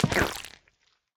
Minecraft Version Minecraft Version snapshot Latest Release | Latest Snapshot snapshot / assets / minecraft / sounds / block / honeyblock / step2.ogg Compare With Compare With Latest Release | Latest Snapshot